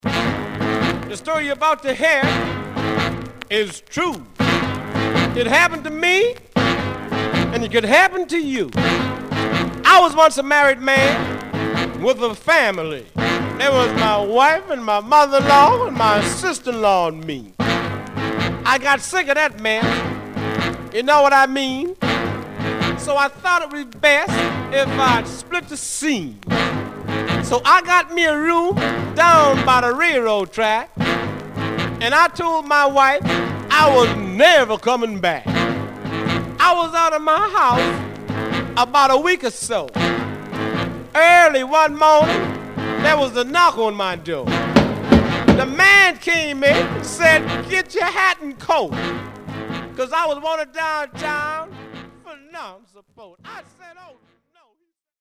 Some surface noise/wear
Stereo/mono Mono
Rythm and Blues